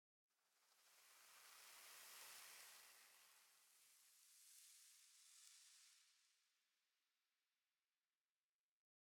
minecraft / sounds / block / sand / sand2.ogg
sand2.ogg